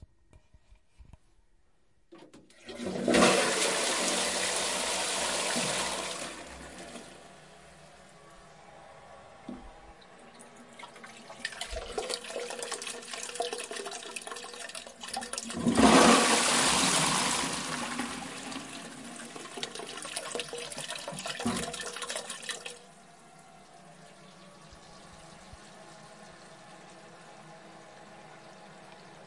杂项 " 厕所冲水（短）
描述：这是我的厕所的声音，几乎是从"里面"录下来的，所以有很多水在流动。
Tag: 浴室 冲水 马桶 马桶冲水